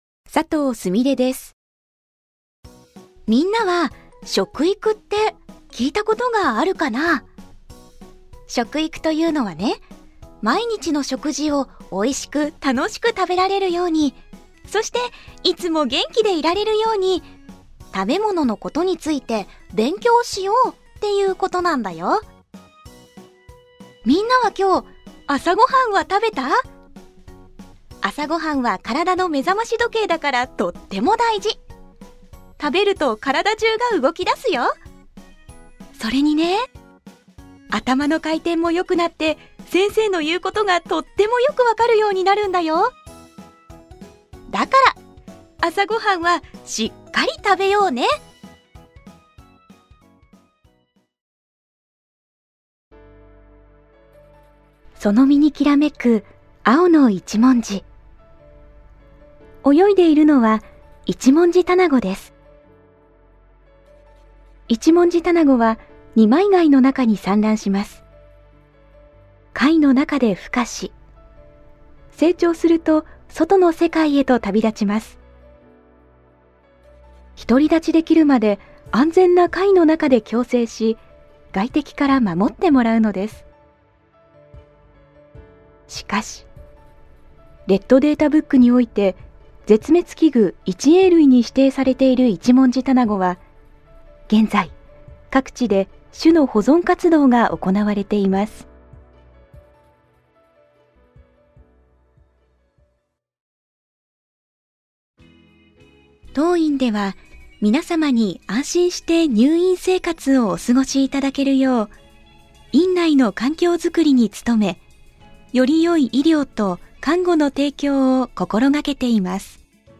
ボイスサンプル
• ピュアな透明感
• 音域：高～中音
• 声の特徴：優しい、おだやか、ピュア